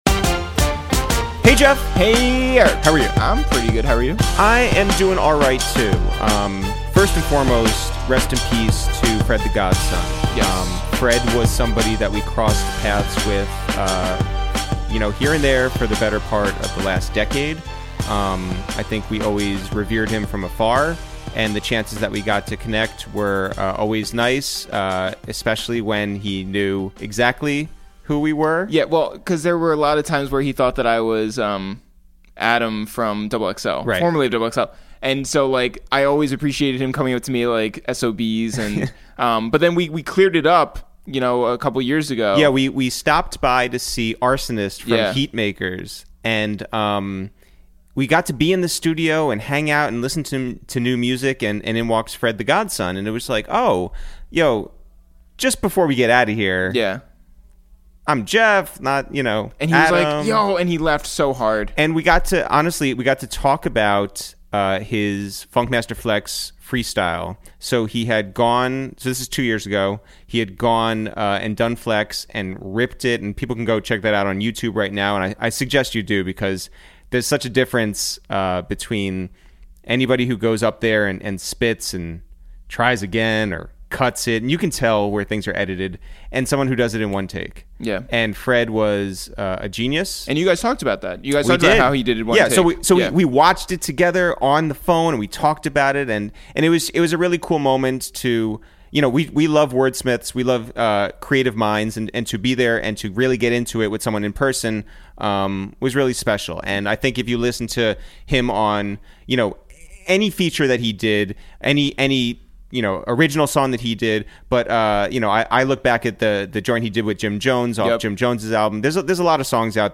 Today on Episode 35 of Quarantine Radio, we make calls from our Upper West Side apartment to Miami to check in on super producer Salaam Remi, and we talk about why he chose to move to Florida after 9/11, what he loves about the culture down there, how long he kept wearing Timb...